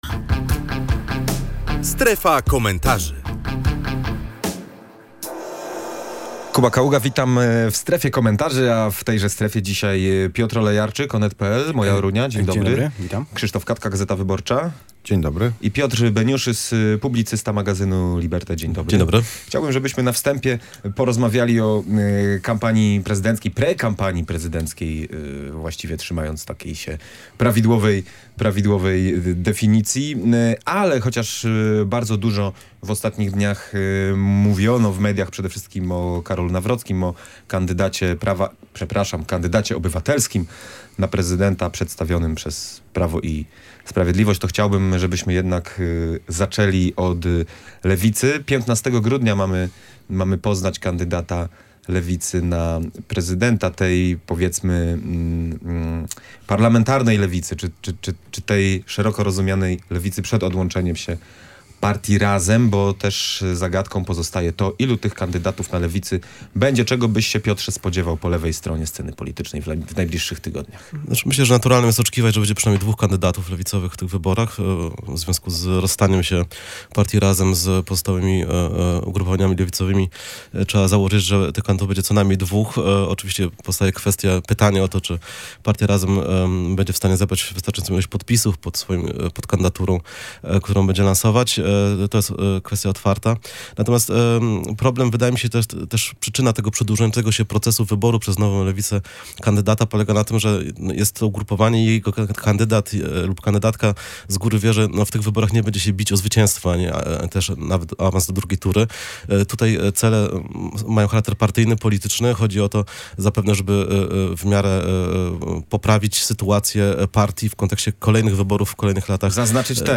Publicyści komentują